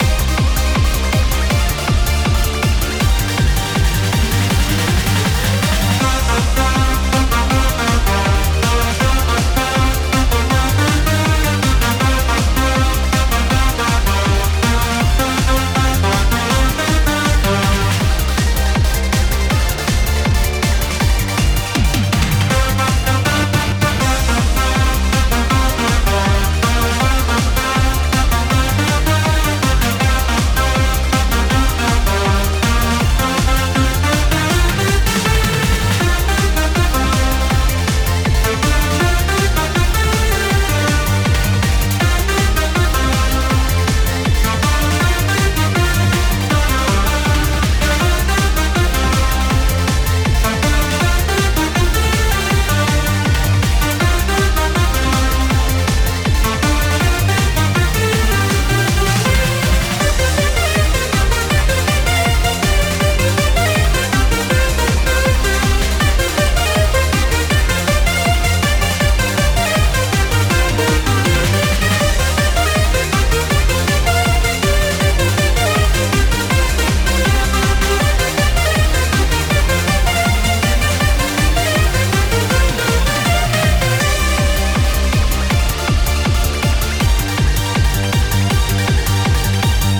Music for racing game.
"Climax" is final lap music. This music is loopable.